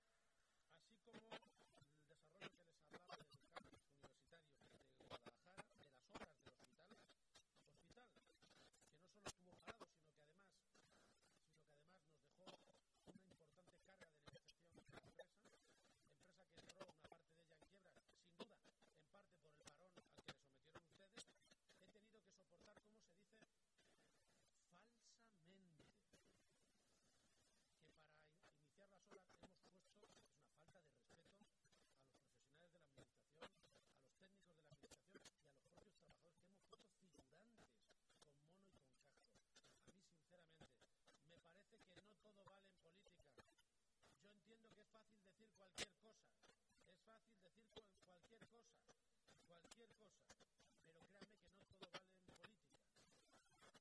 El presidente de Castilla-La Mancha, Emiliano García-Page, habla del Hospital de Guadalajara durante el Debate sobre Estado de la Región.